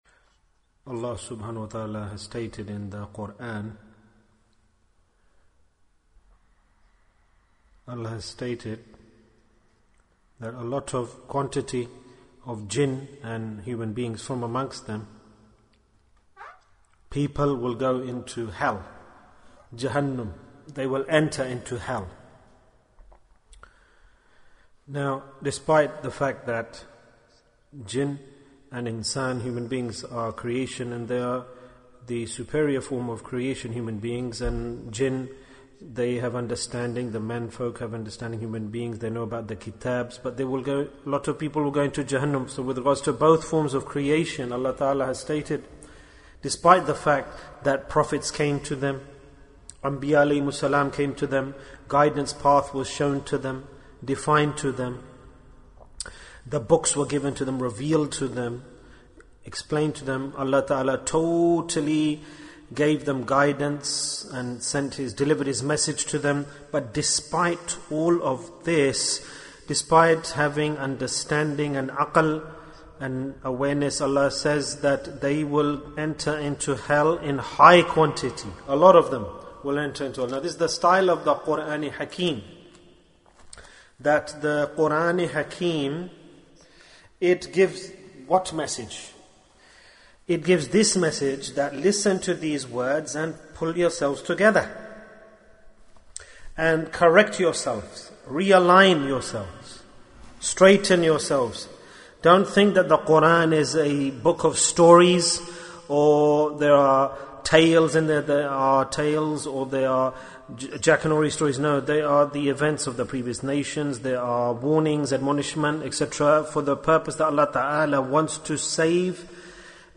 What is the Punishment for Laziness? Bayan, 50 minutes3rd February, 2020